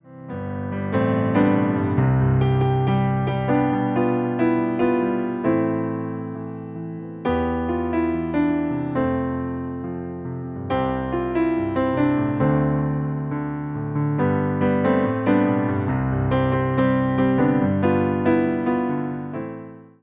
Wersja demonstracyjna:
68 BPM
C – dur